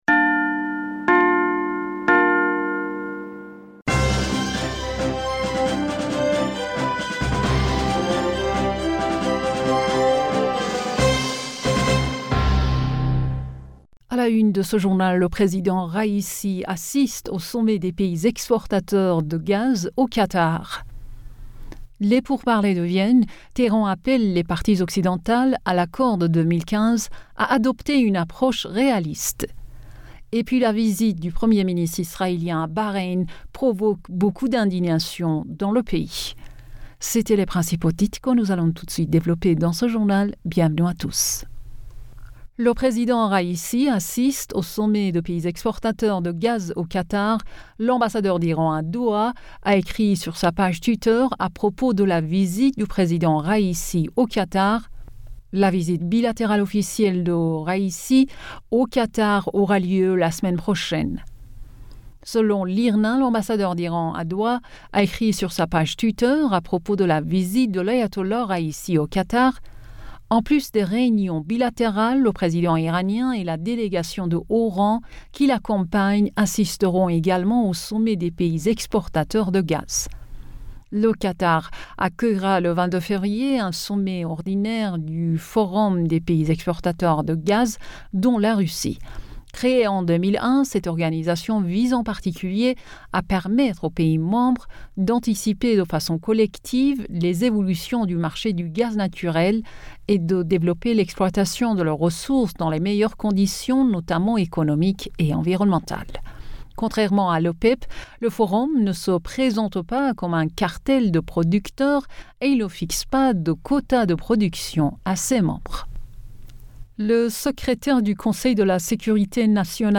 Bulletin d'information Du 16 Fevrier 2022